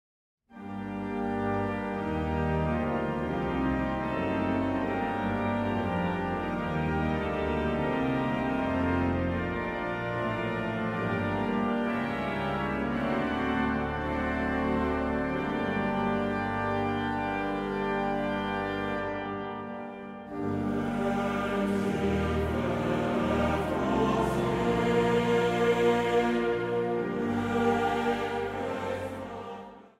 2 stemmen
Zang | Jongerenkoor